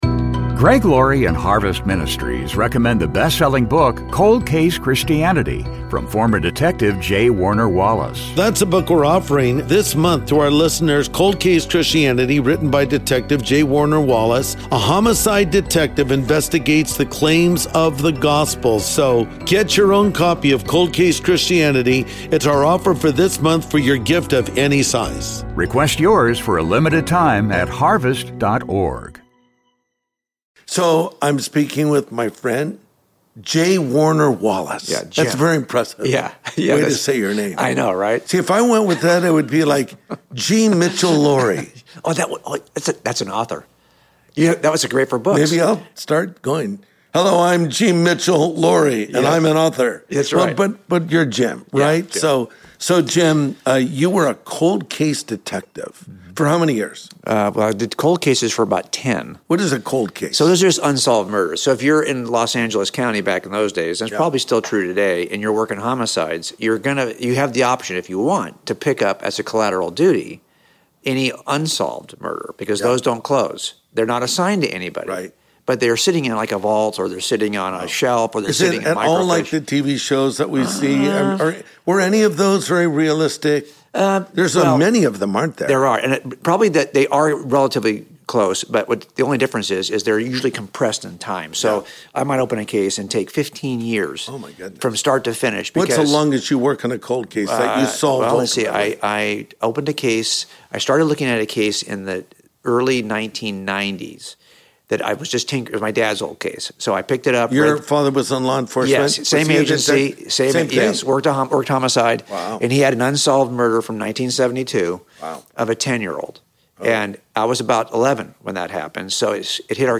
Investigating Scripture's Claims and Answering Skeptics' Tough Questions (J. Warner Wallace Interview)